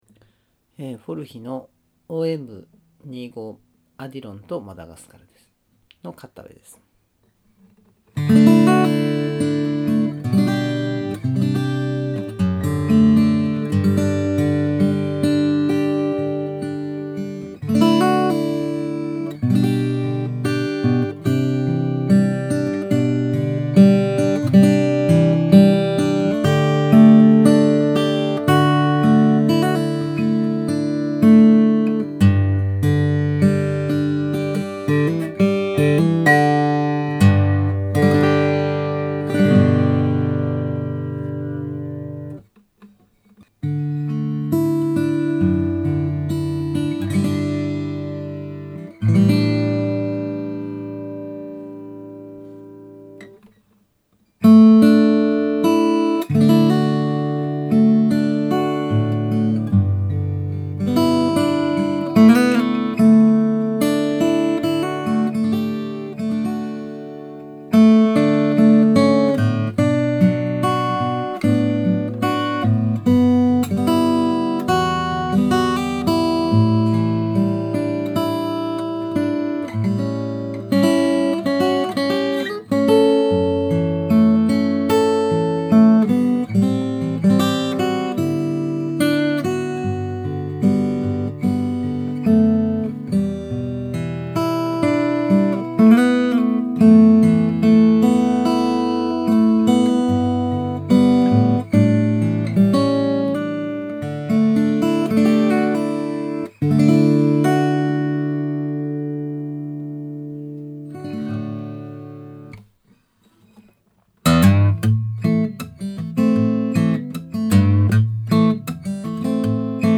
OMなのに低音も十分あります。
アディロンのギターの特徴は低音のパンチにありますね。 6弦を弾いた時に ズウーン と来る感じは非常に気持ちがいいですね。 OMサイズの高音と低音のバランス，ストロークの際の低音なども聴いてみてくださいね。 OM25SGCT アディロン＆マダガスカルの音 ズウーンが聞こえましたか。